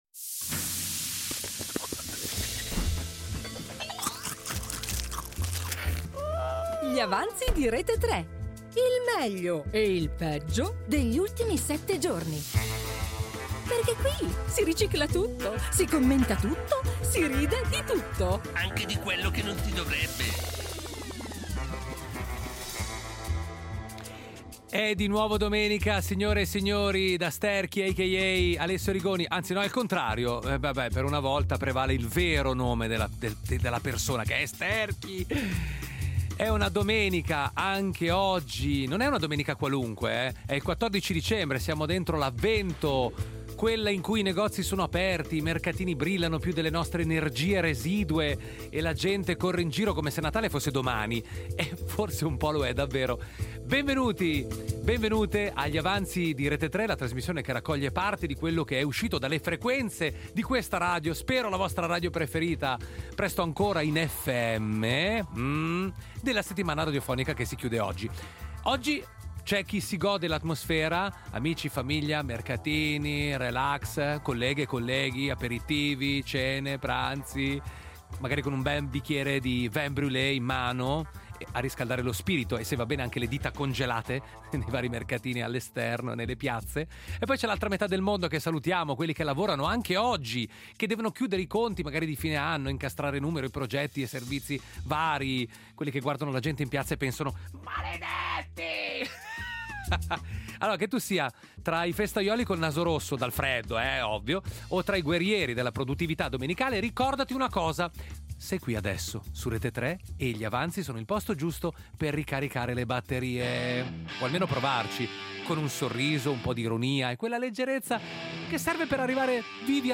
Il pranzo della domenica è finito… ma in radio restano Gli avanzi.
Due ore di musica, momenti memorabili (o dimenticabili), notizie che hanno fatto rumore e altre che hanno solo fatto vibrare il telefono. Un programma che non butta via niente: si ricicla tutto, si commenta tutto, si ride di tutto.